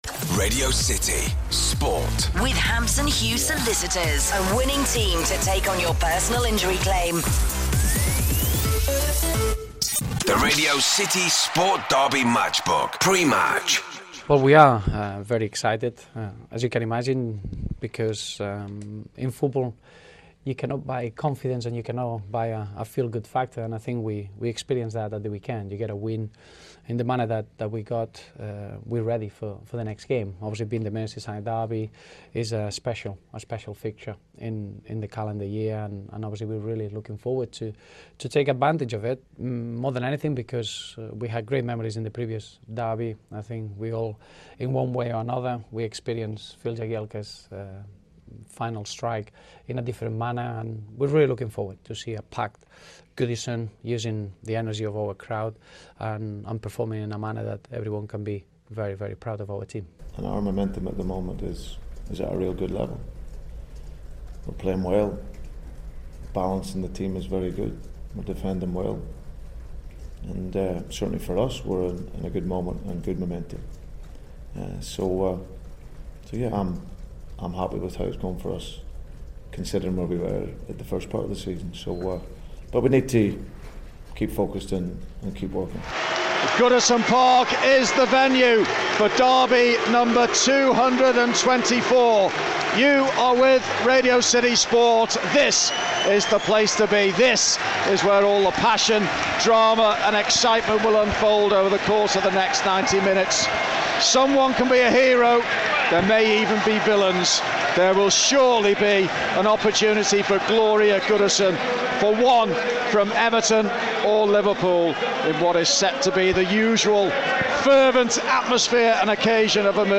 Hear how the 224th Merseyside Derby ended in stalemate at Goodison Park with your commentators